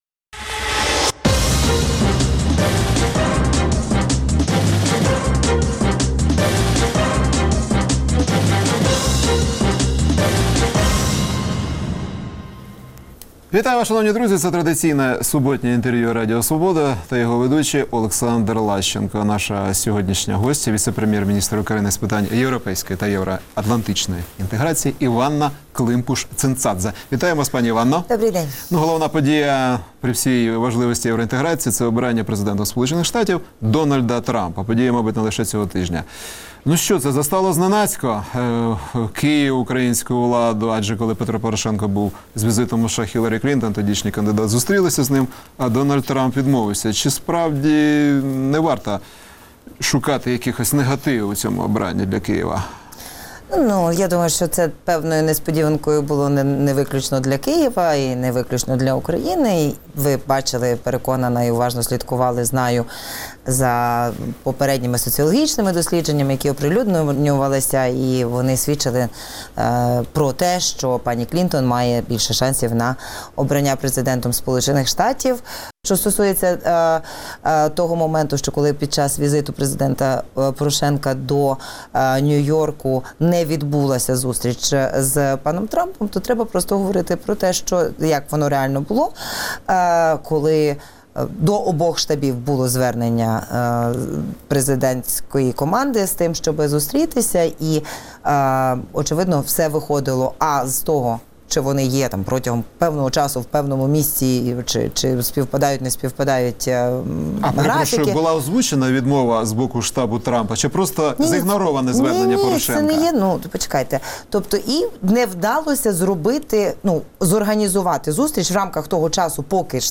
Суботнє інтерв’ю | Коли буде безвіз для України?
Гість програми: Іванна Климпуш-Цинцадзе, віце-прем'єр-міністр з питань європейської та євроатлантичної інтеграції України